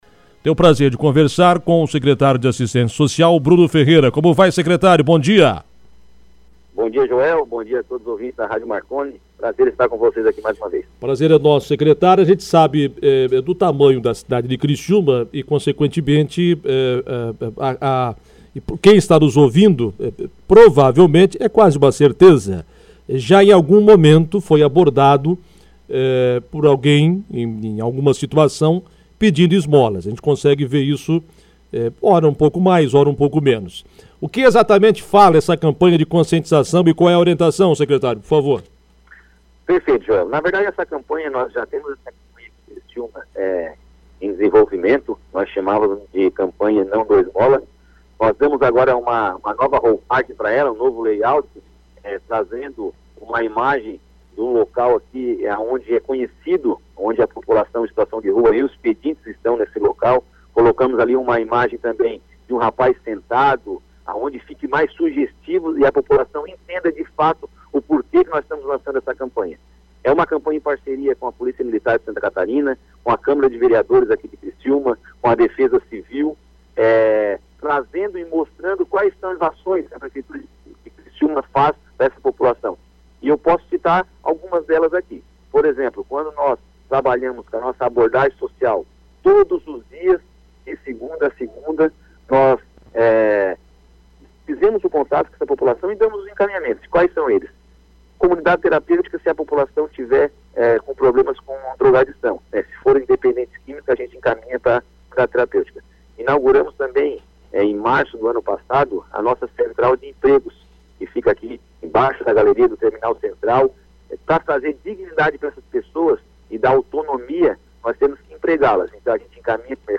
O programa Comando Marconi abordou mais sobre a campanha em entrevista com o secretário Bruno.